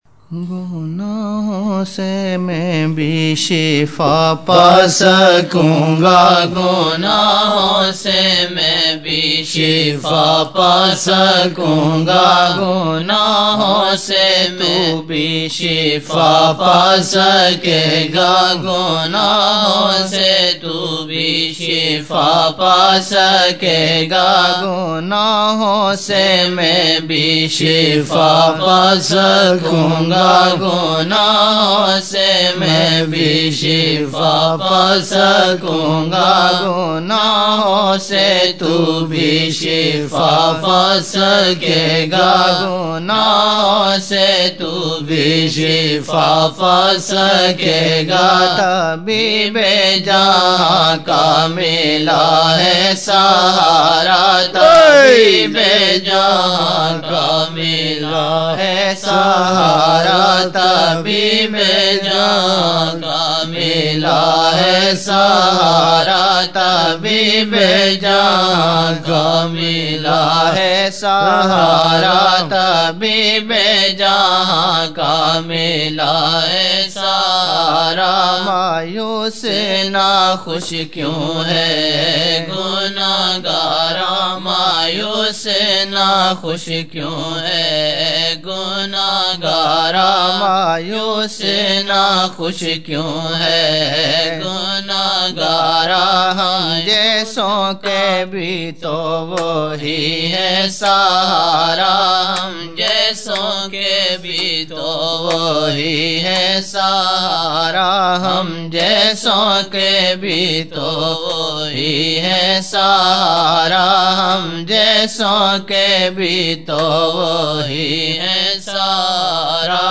Old Naat Shareef